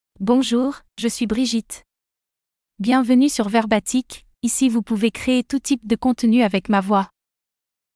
BrigitteFemale French AI voice
Brigitte is a female AI voice for French (France).
Voice sample
Female
Brigitte delivers clear pronunciation with authentic France French intonation, making your content sound professionally produced.